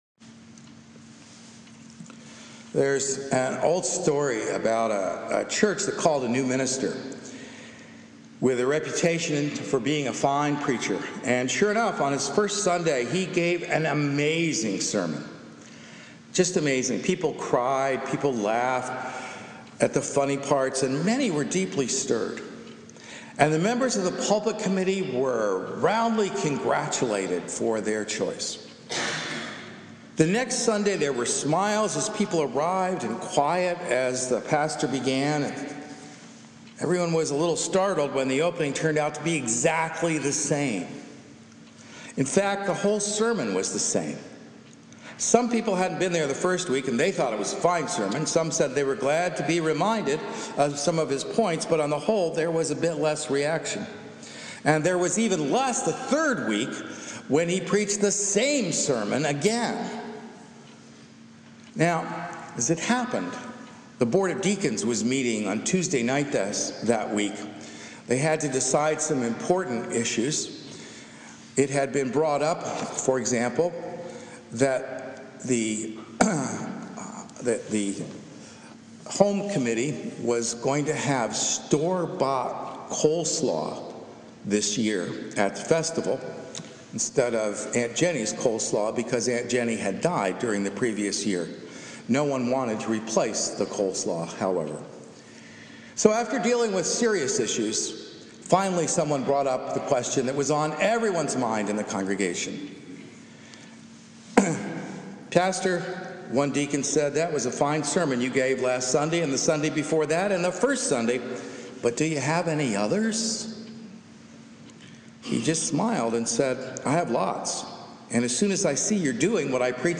A Sermon for the First Congregational Church of Albany, NY